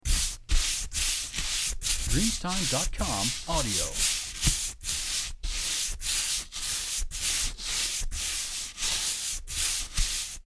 Brushing Fast 10
• SFX